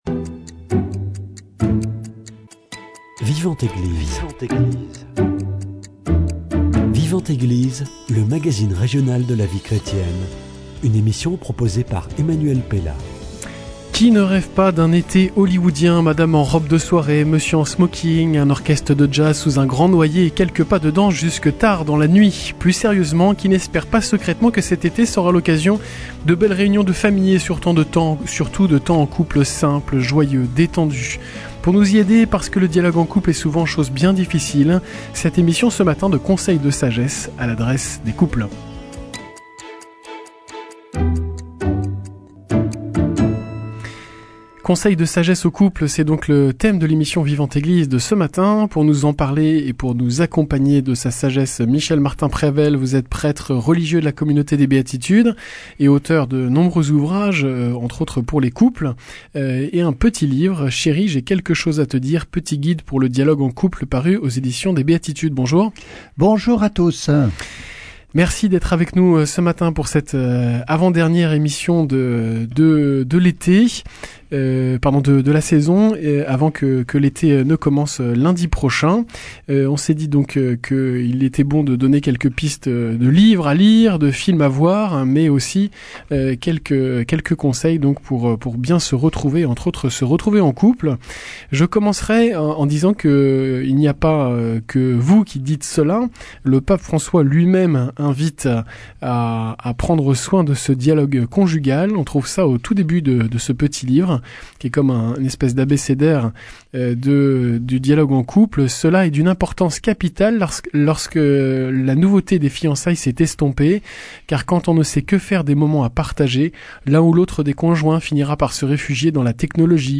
Aussi, pour prendre du temps en couple, savoir comment bien dialoguer, et avoir quelques recettes simples et claires, cette émission de conseils, avec un sage en la matière et un grand accompagnateur de couples.